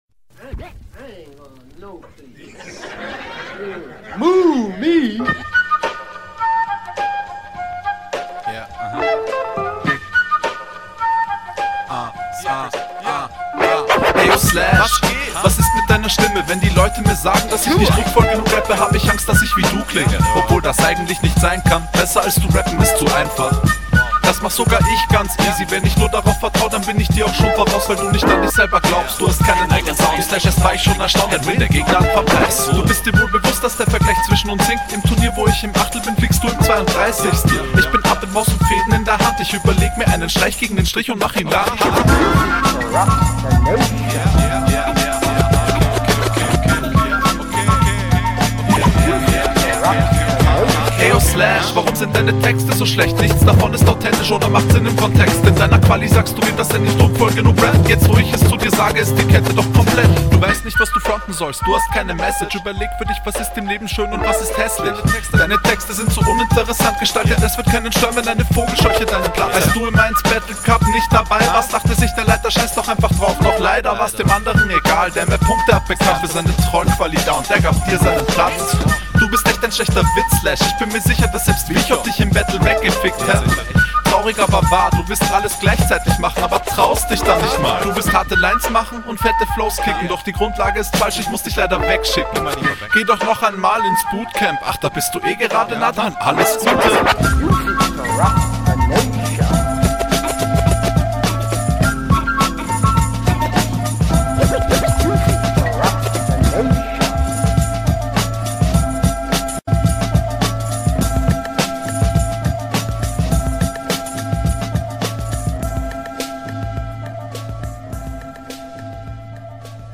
Flow: stabiler oldschool flow. harmoniert gut mit dem beat. ziehst das bis zum ende routiniert …
Flow: cool und gleichzeitig chillig, alles in allem sehr sauber dein flow, ich finde du …